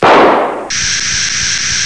00191_Sound_petard.mp3